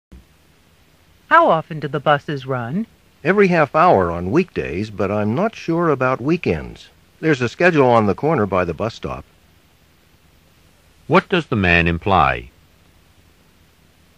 You will hear short conversations between two people. After each conversation, you will hear a question about the conversation.
Conversation 1